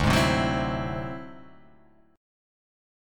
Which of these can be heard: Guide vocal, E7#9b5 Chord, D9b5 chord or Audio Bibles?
D9b5 chord